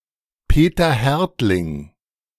Peter Härtling (German: [ˈpeːtɐ ˈhɛʁtlɪŋ]